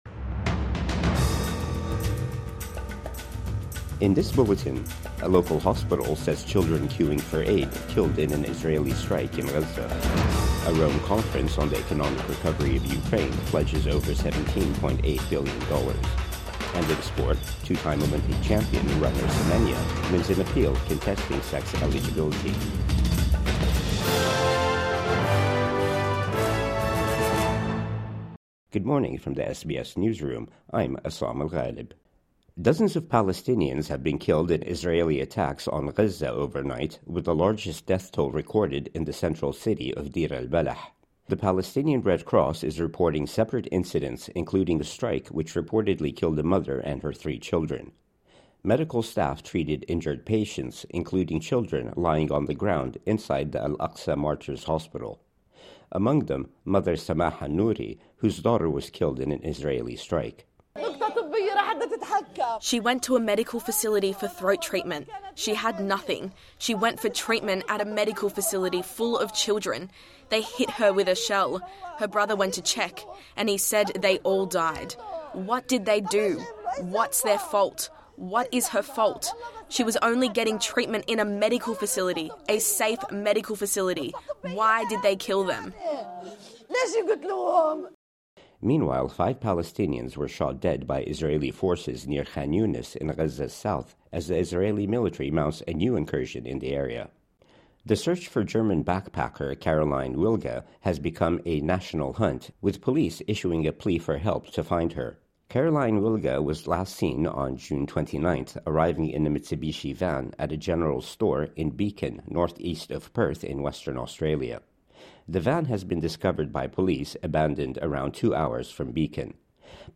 Children queuing for aid killed in Israeli strike in Gaza | Morning News Bulletin 11 July 2025